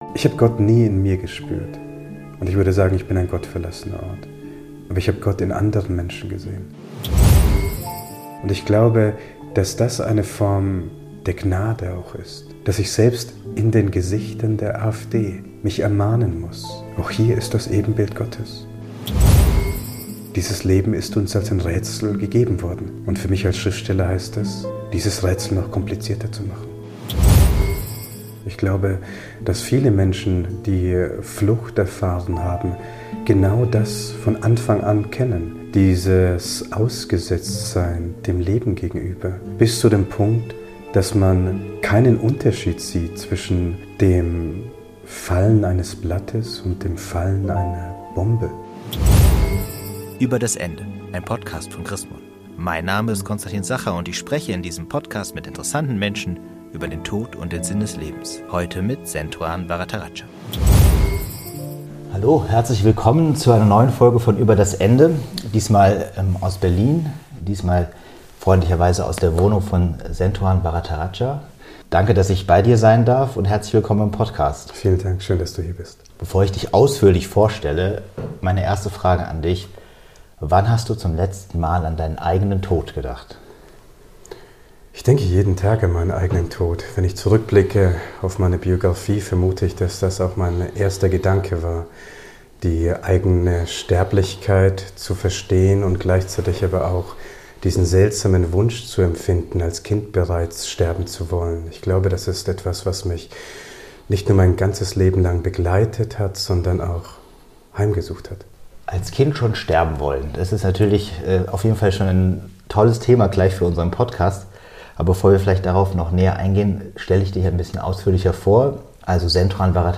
Gespräche über Tod, Sterben und Sinn des Lebens